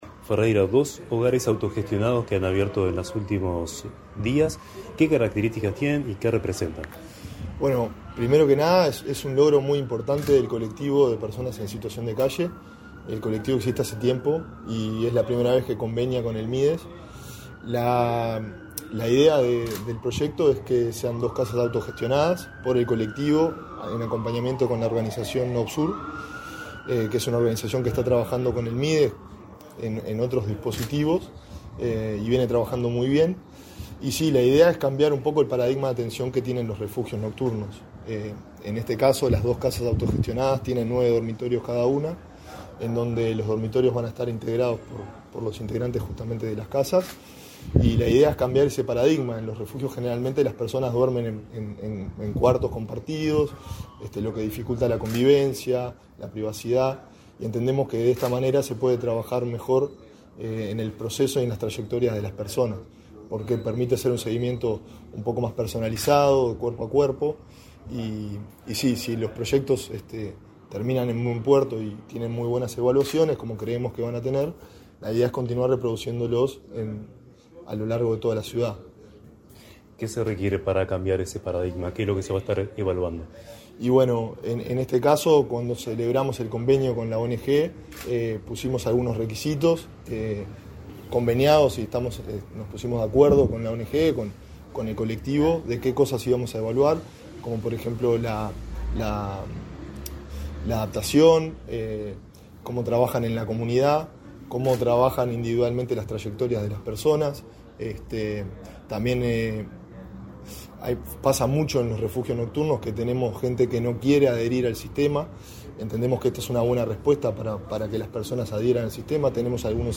Entrevista al adjunto a la Dirección Nacional de Protección Social del Mides, Wilson Ferreira
Entrevista al adjunto a la Dirección Nacional de Protección Social del Mides, Wilson Ferreira 29/12/2023 Compartir Facebook X Copiar enlace WhatsApp LinkedIn El Ministerio de Desarrollo Social (Mides) inauguró, este 29 de diciembre, dos casas autogestionadas por el colectivo de personas en situación de calle NITEP y la organización OBSUR. Tras el evento, Ferreira realizó declaraciones a Comunicación Presidencial.